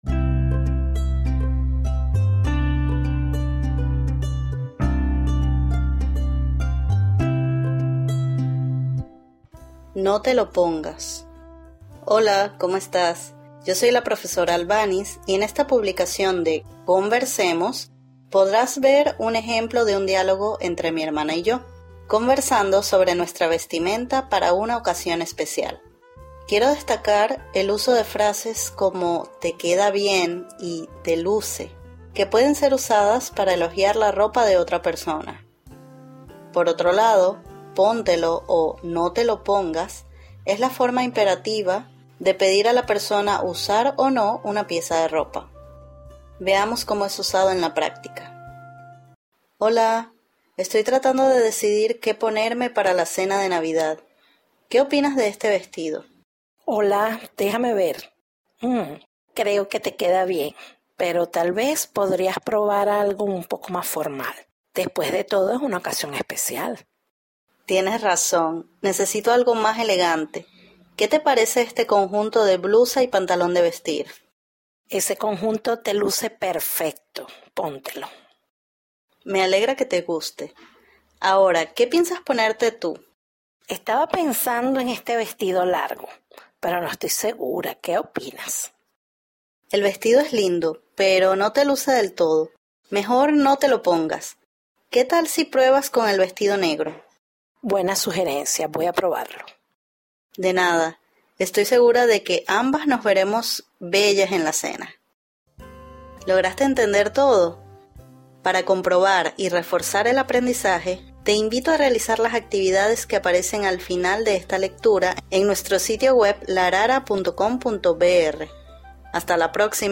Un diálogo sobre qué ropa usar en una ocasión especial.